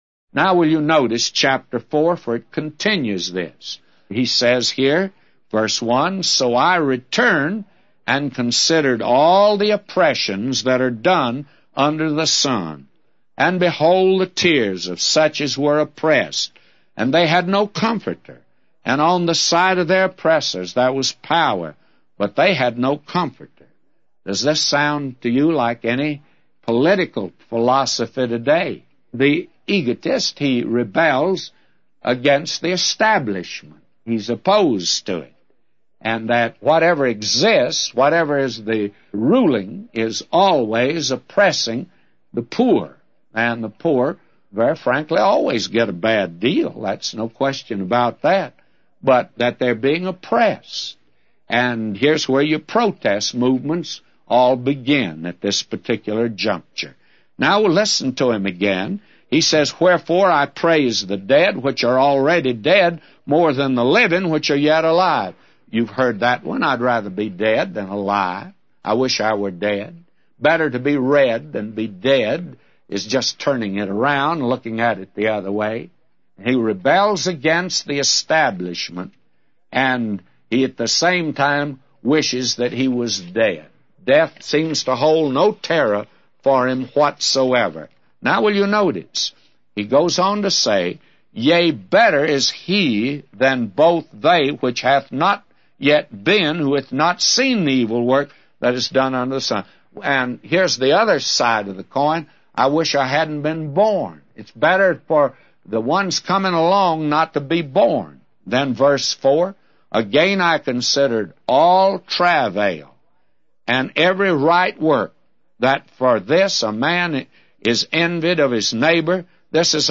A Commentary By J Vernon MCgee For Ecclesiastes 4:1-999